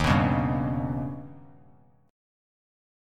Eb+7 chord